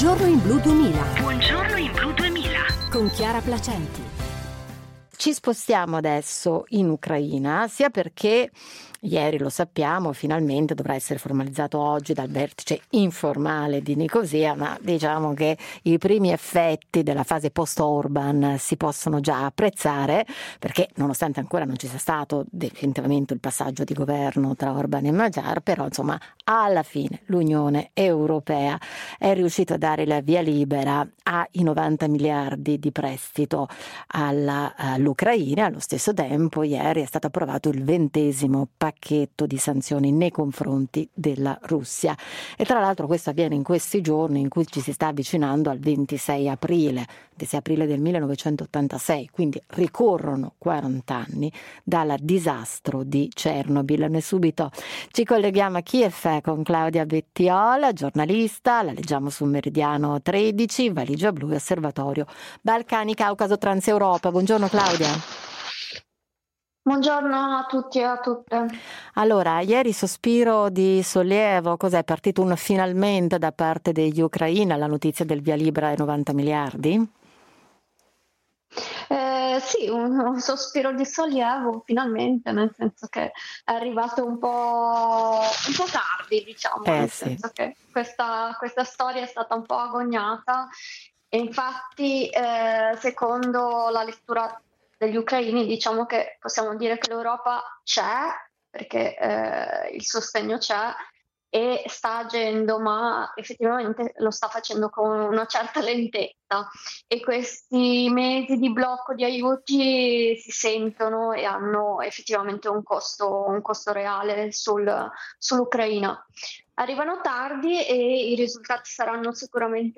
Il Comitato dei rappresentanti permanenti presso l’Ue ha sbloccato il nuovo prestito di 90 miliardi per l’Ucraina e il 20esimo pacchetto di sanzioni a Mosca. In diretta da Kyiv